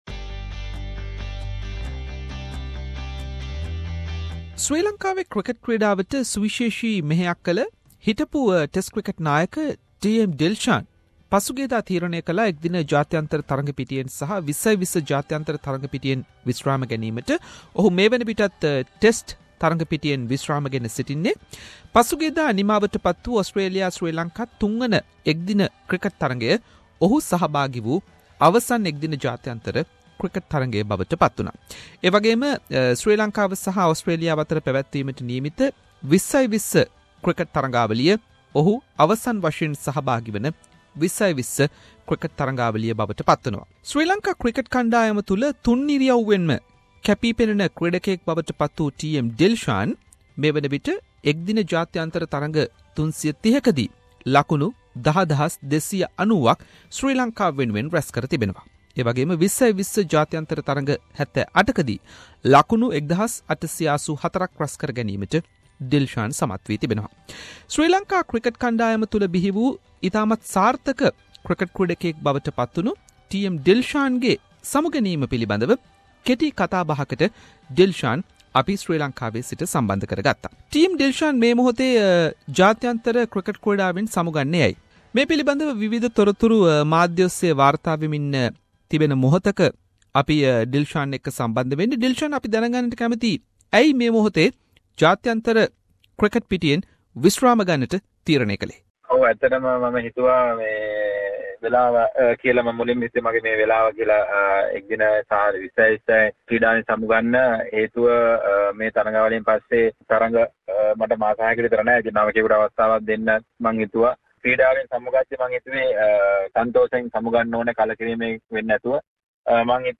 Special interview with retiring Sri Lankan cricketer TM Dilshan regarding his 17 years cricket career.